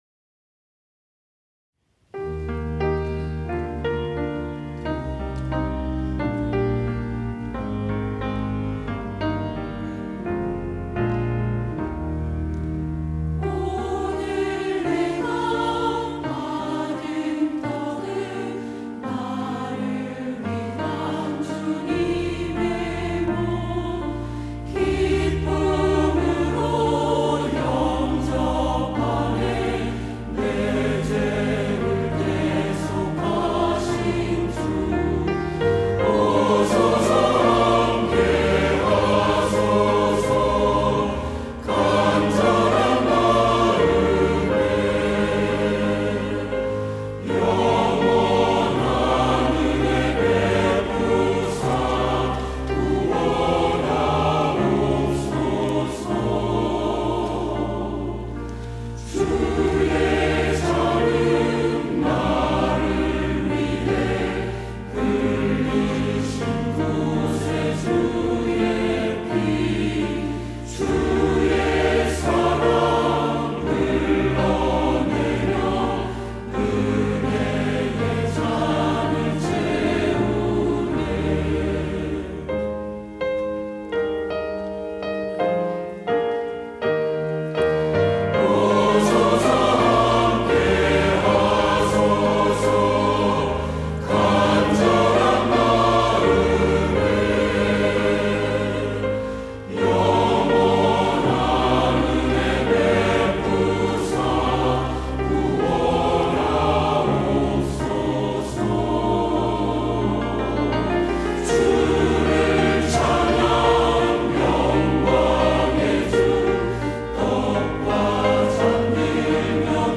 시온(주일1부) - 성찬
찬양대